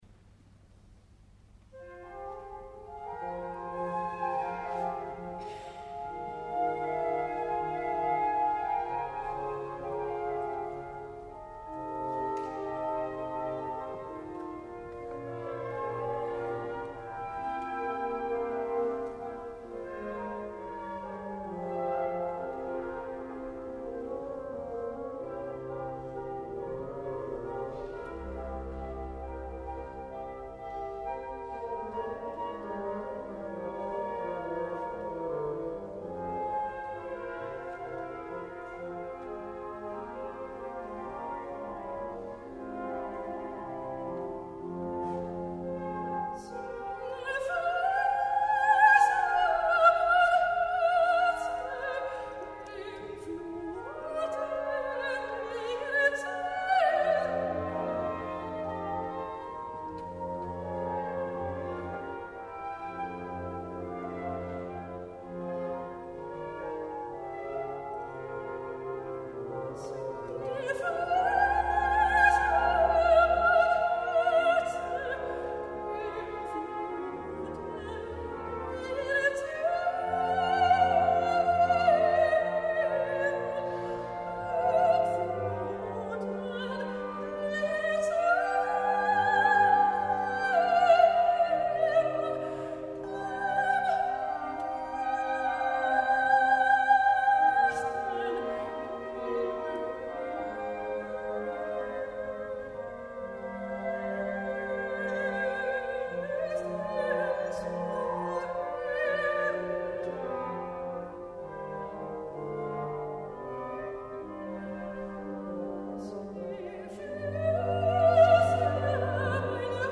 sopraan Muziekfragmenten